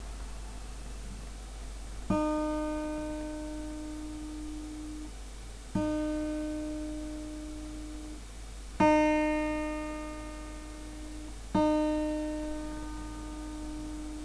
Вот первая струна. Так пойдет или тянуть?